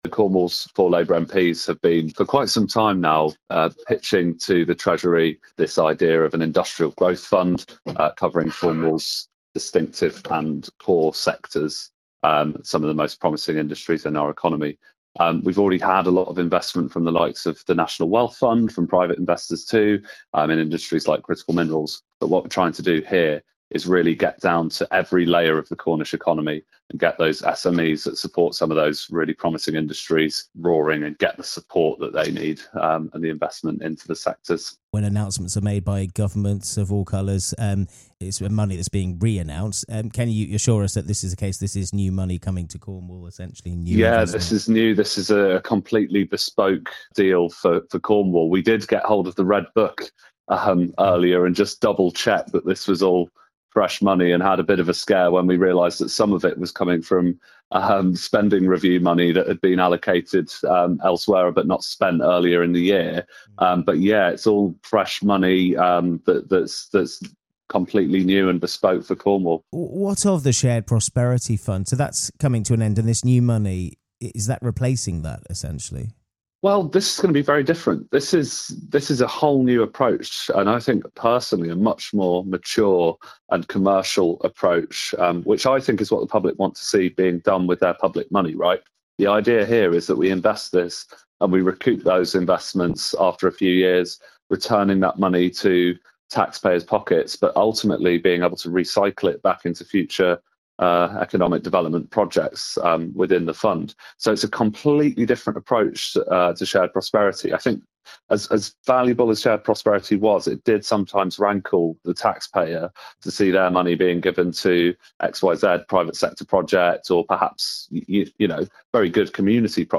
You can hear Noah Law speaking to Radio Newquay about the fund - addressing concerns over rising taxes and the impact of minimum wage changes on small businesses in the town - PRESS PLAY below...